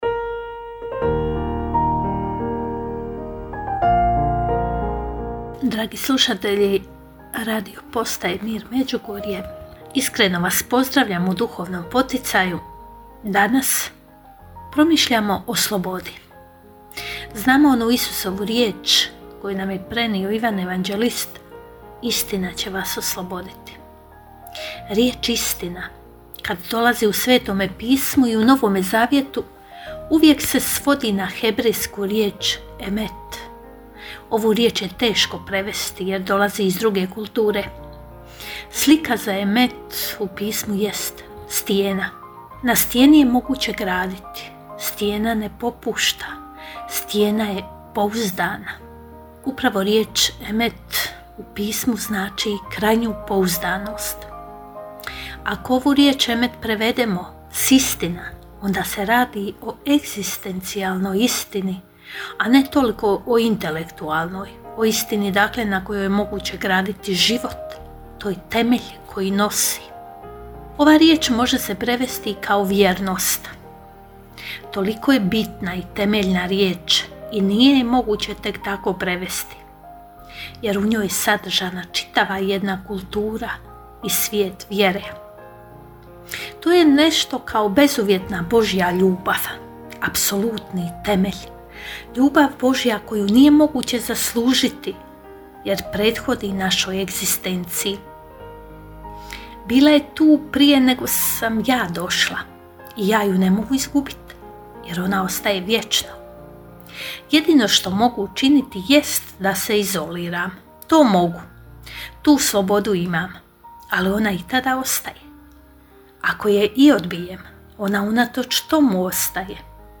Kratku emisiju ‘Duhovni poticaj – Živo vrelo’ slušatelji Radiopostaje Mir Međugorje mogu čuti od ponedjeljka do subote u 3 sata, te u 7:10. Emisije priređuju svećenici i časne sestre u tjednim ciklusima.